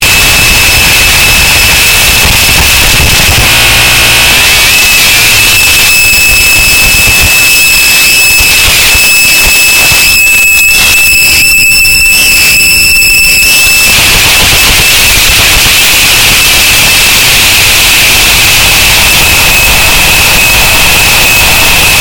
На этой странице представлены таинственные звуки, записанные в глубинах скважин.
Звук вхождения сверлильной головки в грунт